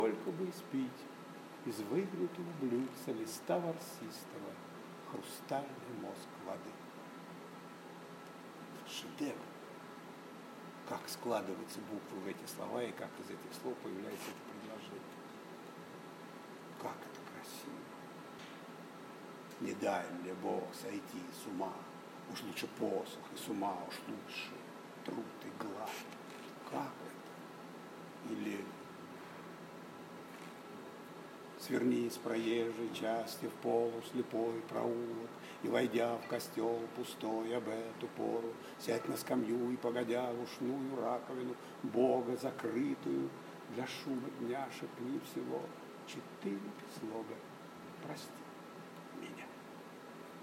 Валерий Баринов рассказал о планах работы в Алтайском крае и прочитал стихи